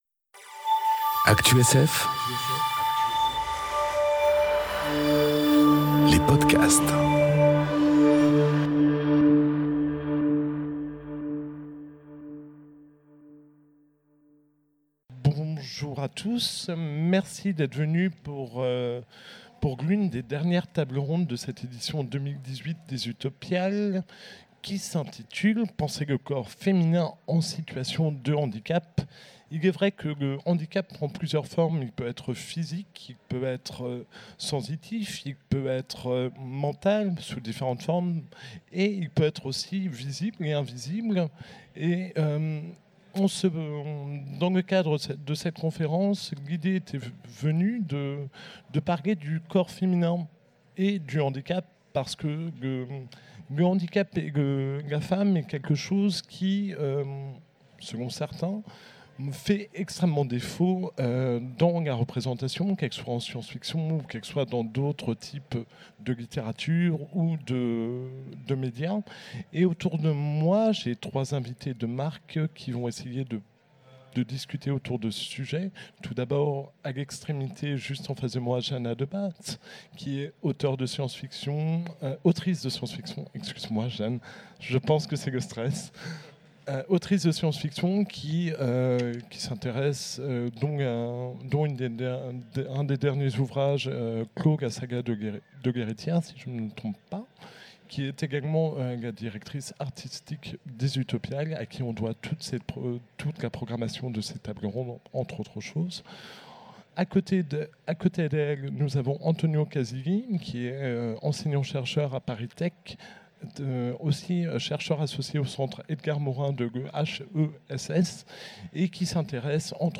Conférence Penser le corps féminin en situation de handicap enregistrée aux Utopiales 2018